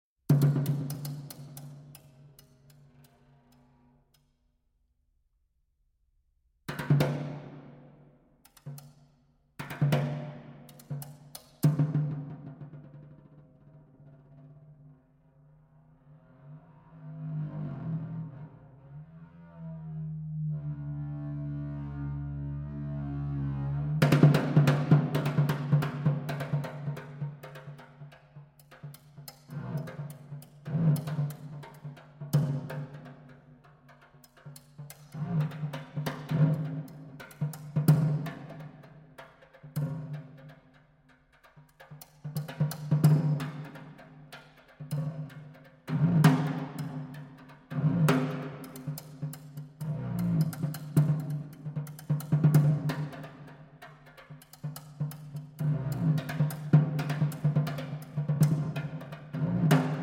Snare Drum